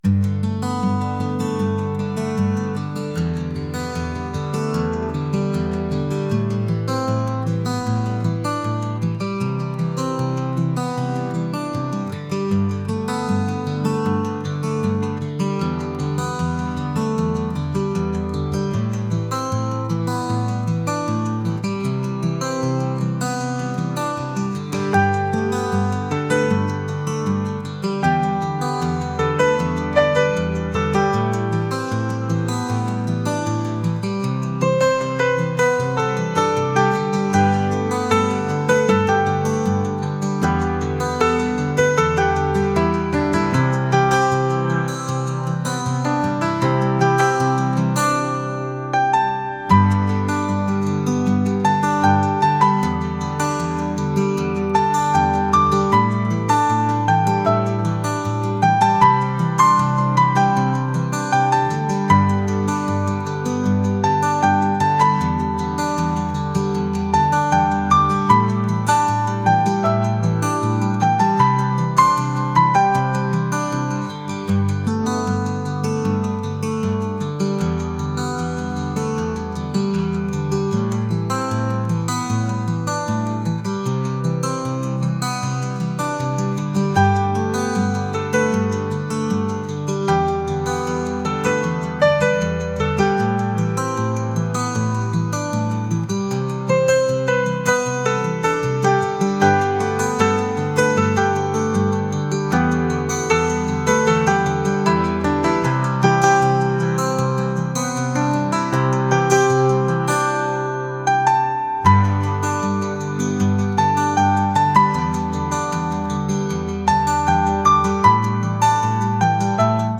acoustic | indie | folk